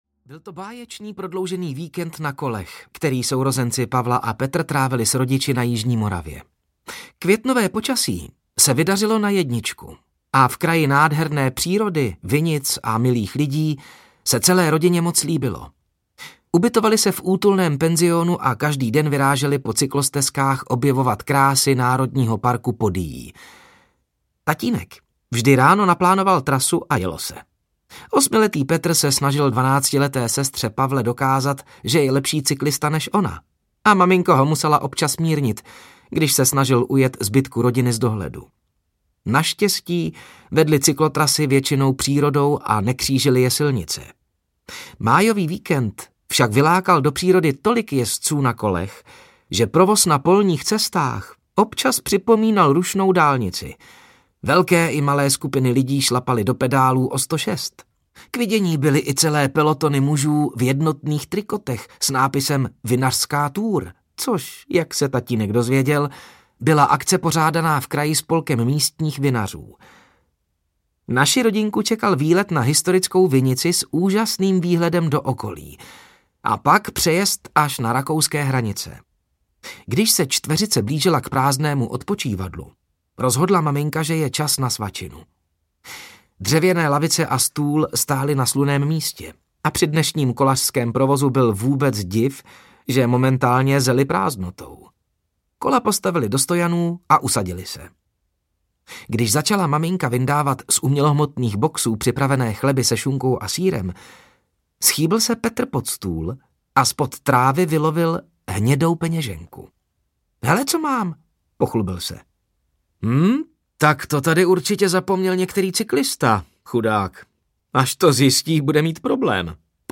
Pátrači na stopě audiokniha
Ukázka z knihy